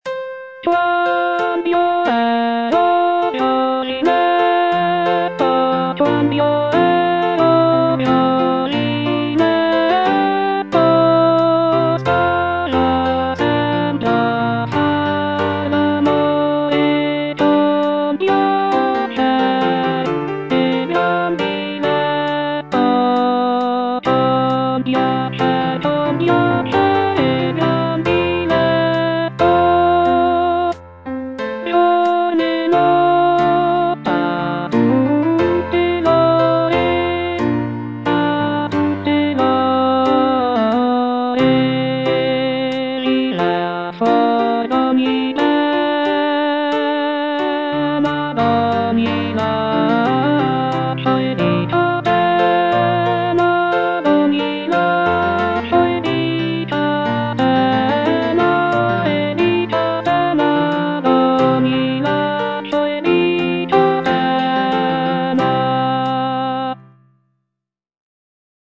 G. GABRIELI - QUAND'IO ERO GIOVINETTO Quand'io ero giovinetto - Alto (Voice with metronome) Ads stop: auto-stop
Gabrieli's use of polyphonic textures and rich harmonies makes this madrigal a captivating example of Renaissance choral music.